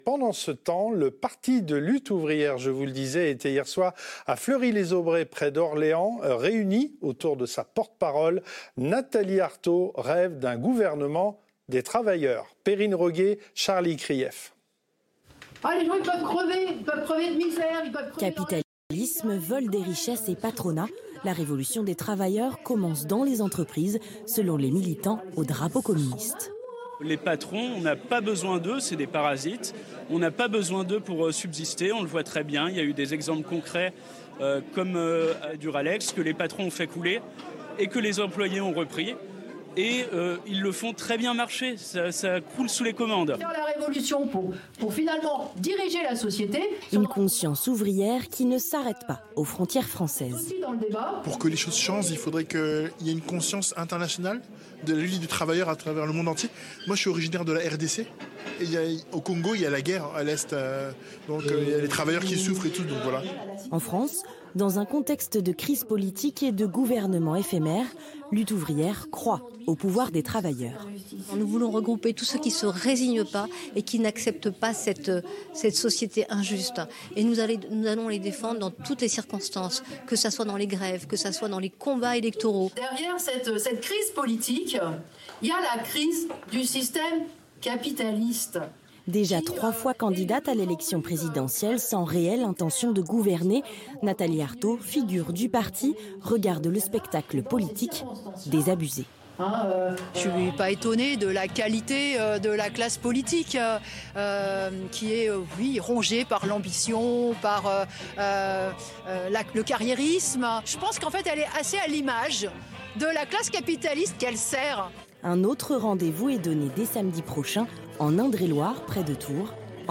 Fête à Orléans : Reportage France 3